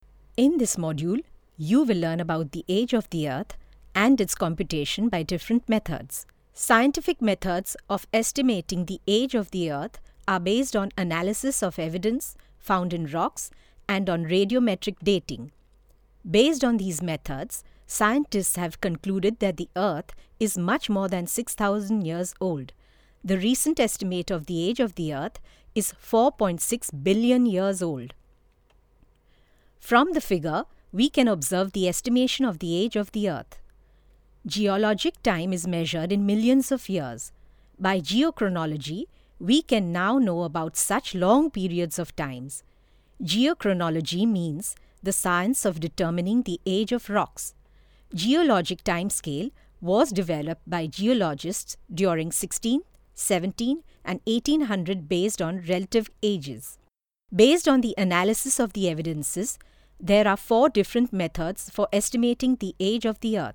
Clear diction and speech. Correct pronunciation, soft, warm, fun, sarcastic. Can modulate different characters.
Sprechprobe: Werbung (Muttersprache):